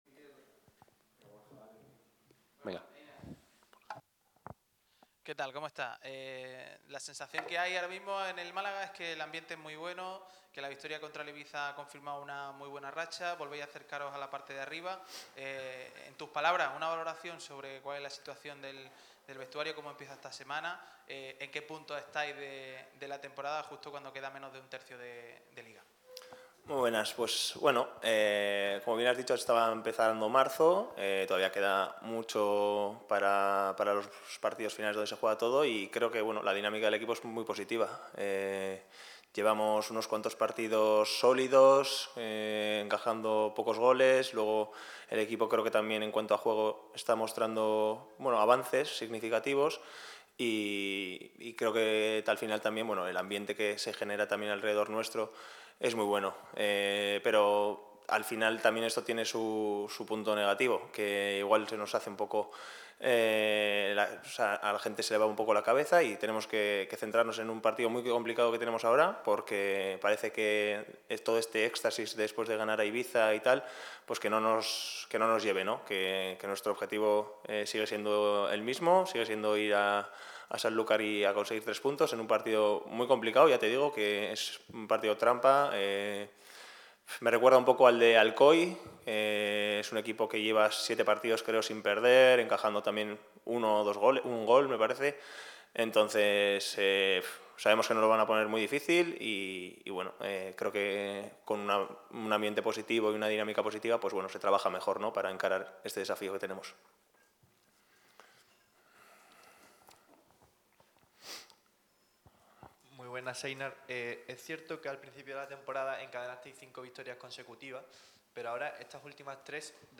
El defensa central de la entidad de Martiricos ha comparecido ante los medios con motivo de la previa del duelo que enfrentará al Málaga CF contra el Atlético Sanluqueño este domingo.